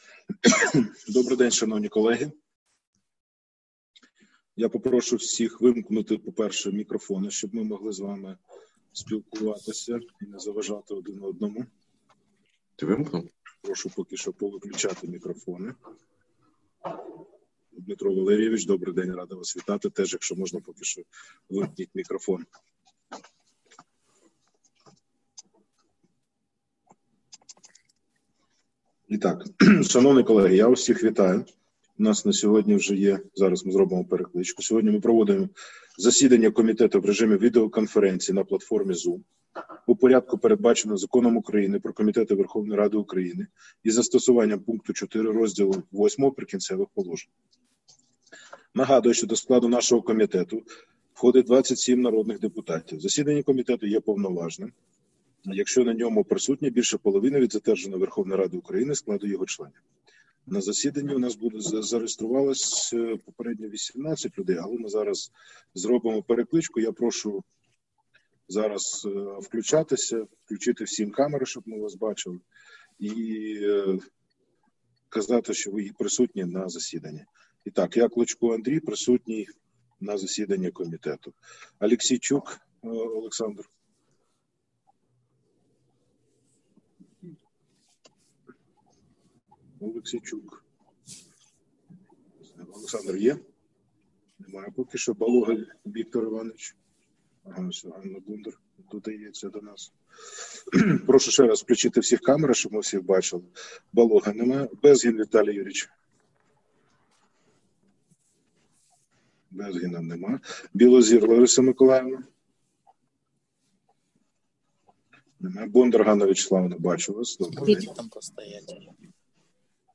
Назва файлу - Аудіозапис засідання Комітету від 9 грудня 2020 року